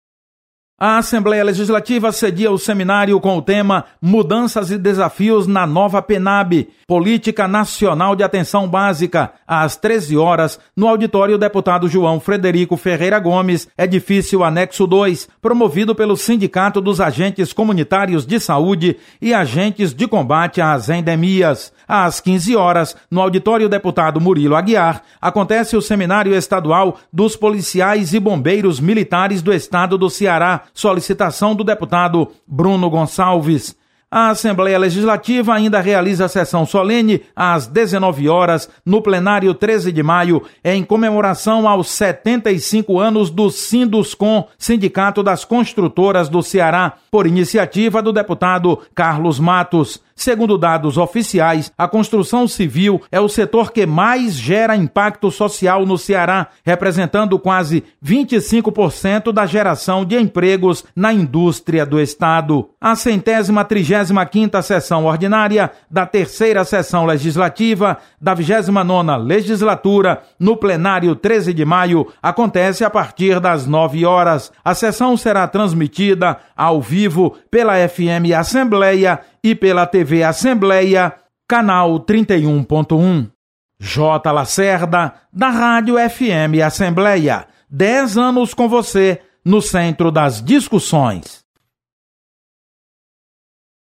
Acompanhe as informações das atividades da Assembleia Legislativa nesta sexta-feira (27/10). Repórter